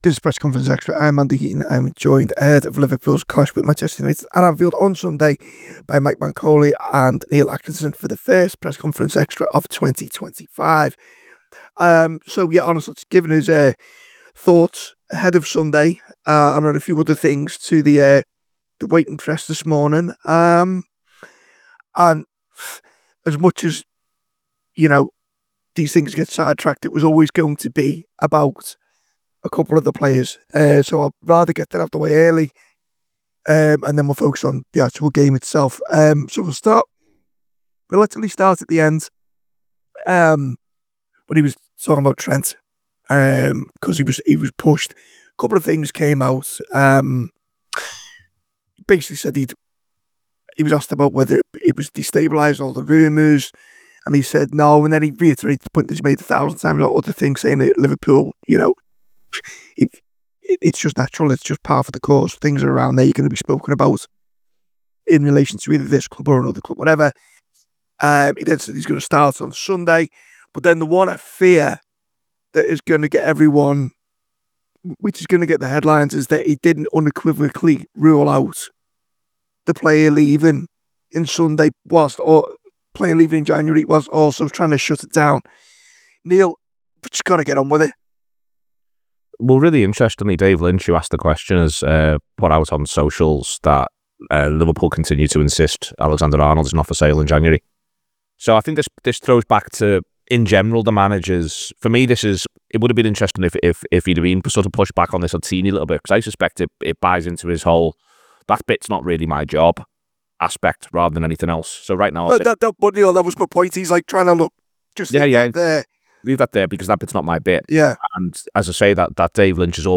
Below is a clip from the show – subscribe for more on the Liverpool v Manchester United press conference…